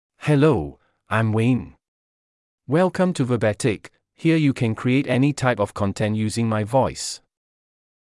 MaleEnglish (Singapore)
Wayne — Male English AI voice
Wayne is a male AI voice for English (Singapore).
Voice sample
Male
English (Singapore)